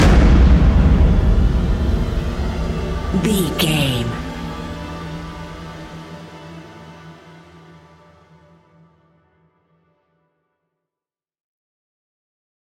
Fast paced
In-crescendo
Thriller
Ionian/Major
E♭
industrial
dark ambient
EBM
synths